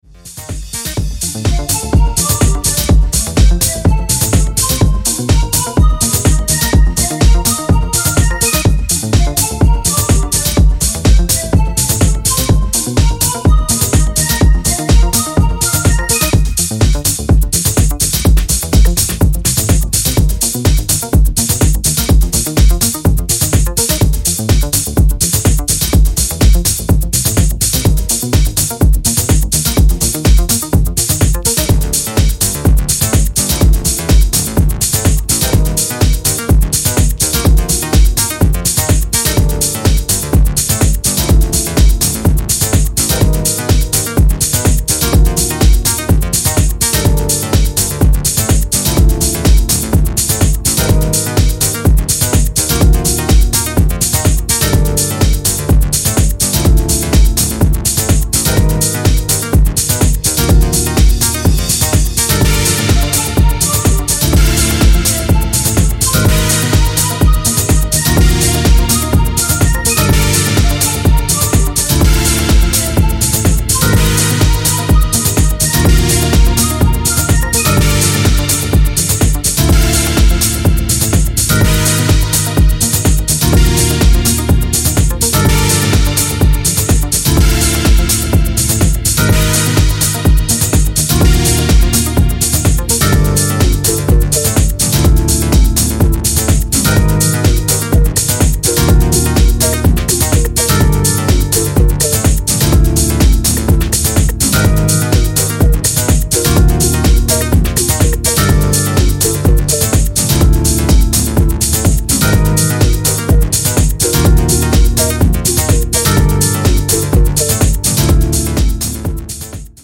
a tough remix with more synths and stronger beats